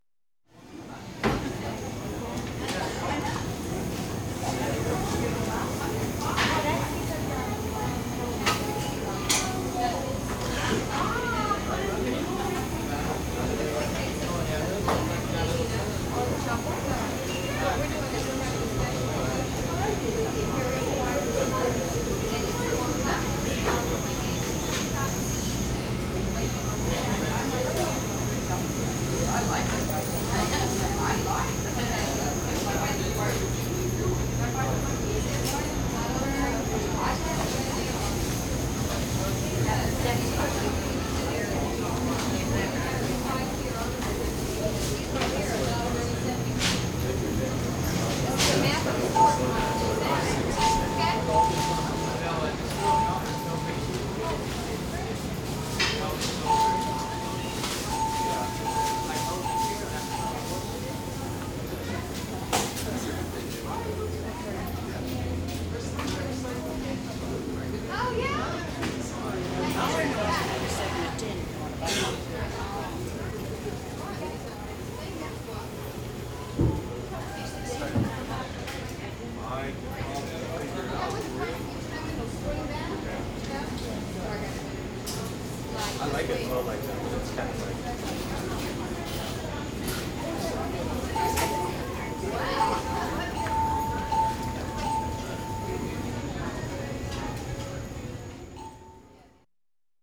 ambience
Department Store Ambience - Crowd, Voices, Warning Bell